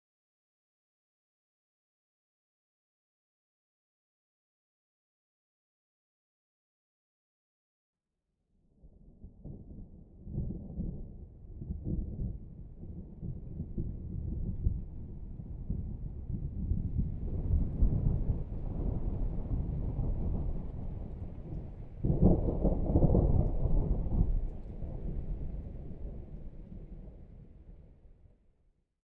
Atmospherics / gamedata / sounds / nature / very_far1.ogg
very_far1.ogg